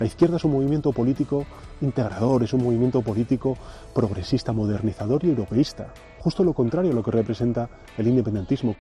Declaraciones de Pedro Sánchez